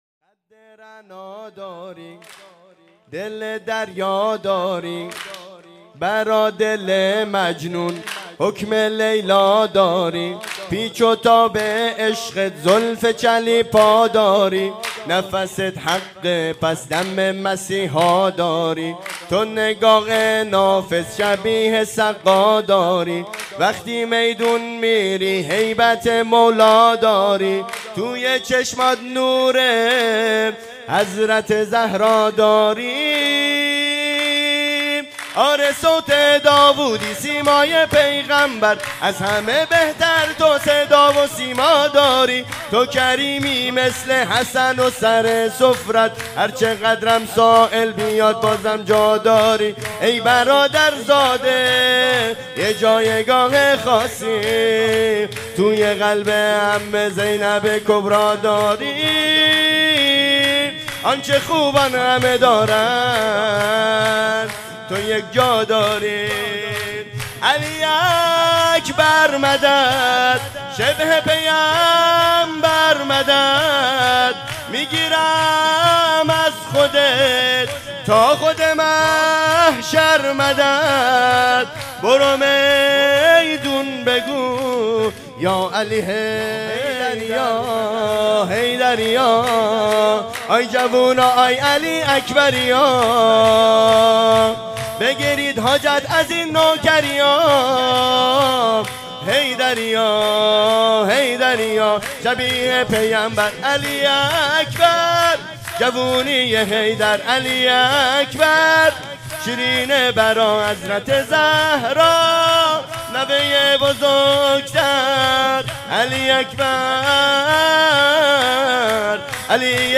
ویژه مراسم جشن بزرگ ولادت امام زمان(عج) و حضرت علی اکبر(ع) و جشن پیروزی انقلاب